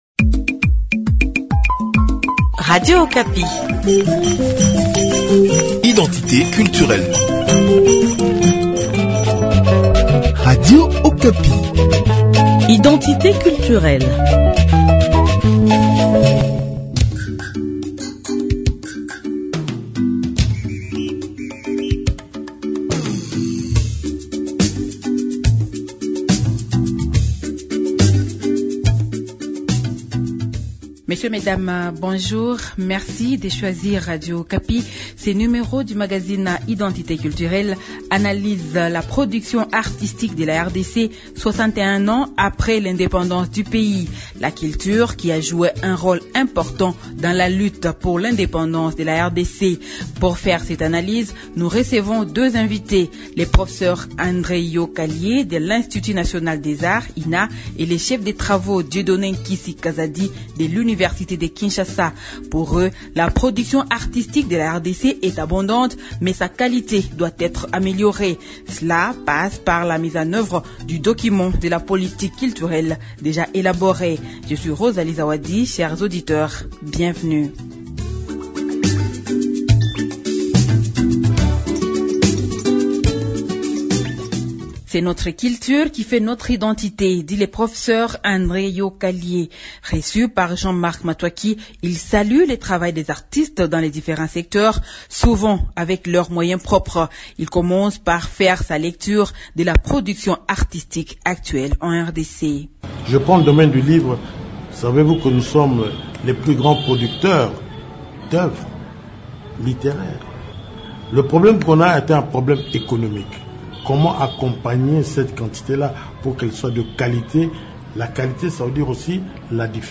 Pour faire cette analyse, nous recevons deux invités